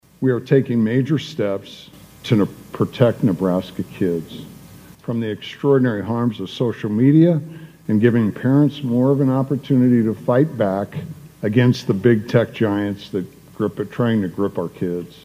At the session's conclusion, Governor Jim Pillen praised the legislation.